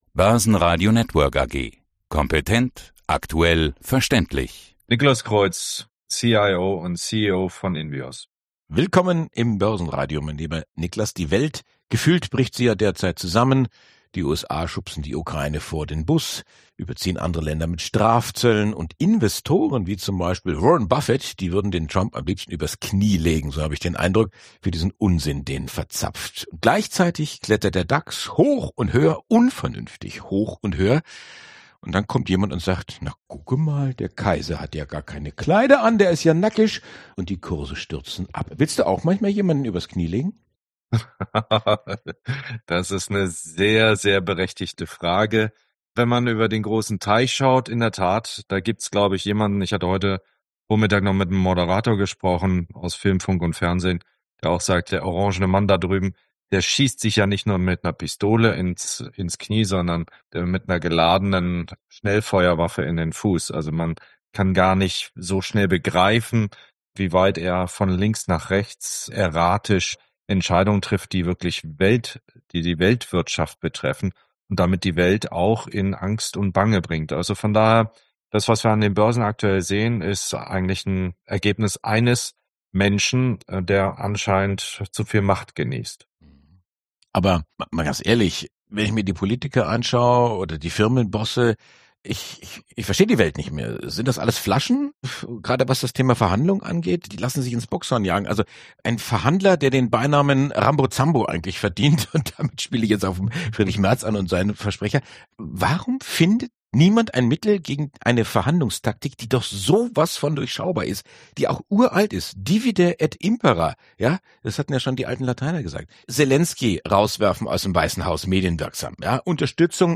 Langfristiges Denken hilft, die Marktstürme zu überstehen. Zum Börsenradio-Interview